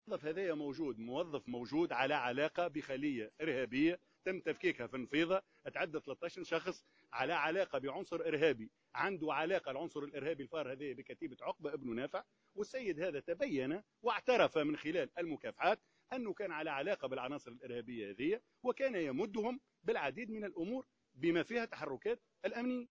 خلال ندوة صحفية اليوم